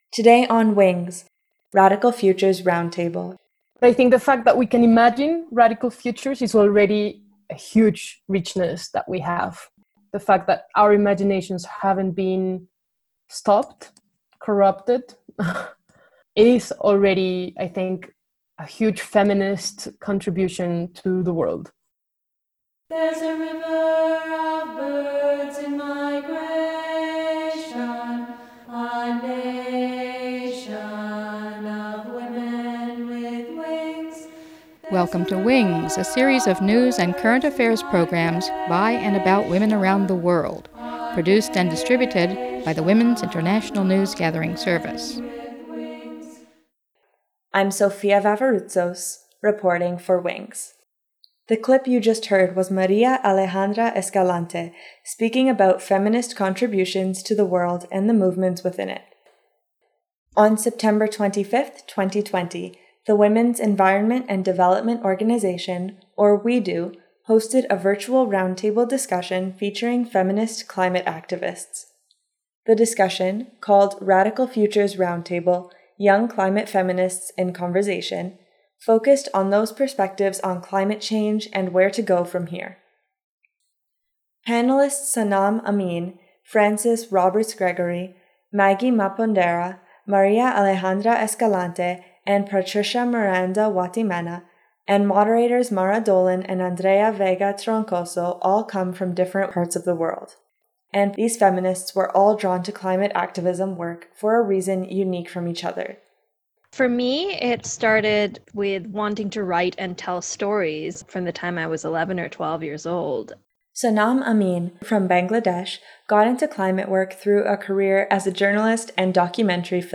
Young Climate Feminist Leaders Talk
Panel by WEDO